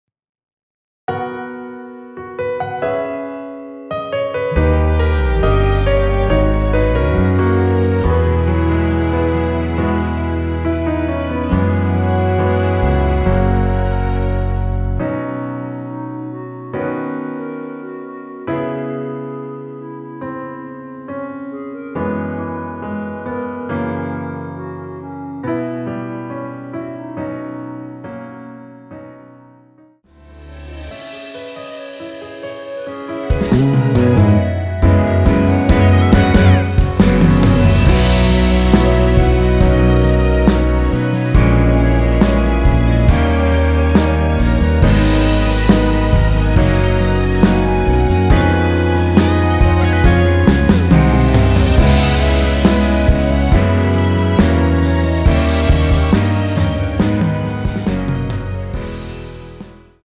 원키에서(-1)내린 멜로디 포함된 MR입니다.(미리듣기 확인)
앨범 | O.S.T
◈ 곡명 옆 (-1)은 반음 내림, (+1)은 반음 올림 입니다.
앞부분30초, 뒷부분30초씩 편집해서 올려 드리고 있습니다.
중간에 음이 끈어지고 다시 나오는 이유는